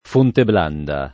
Fontebranda [fontebr#nda] (raro Fonte Branda [id.]) top.